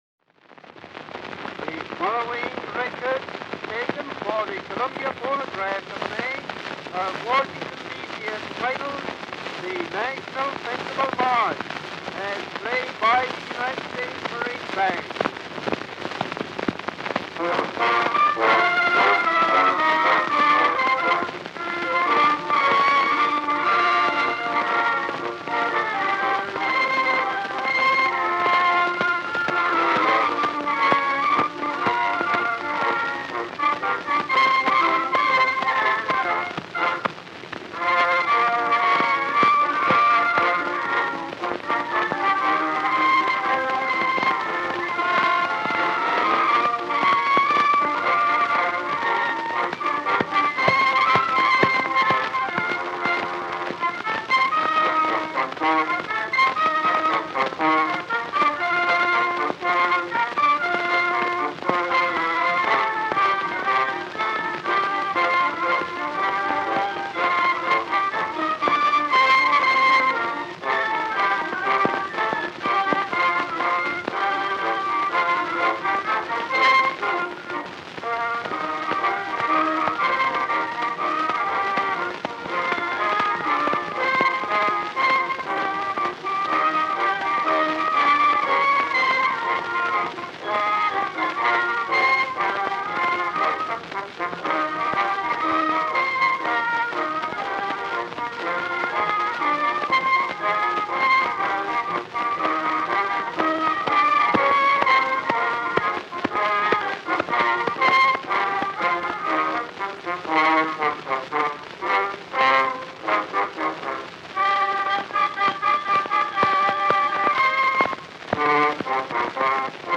Disc 1: Early Acoustic Recordings
March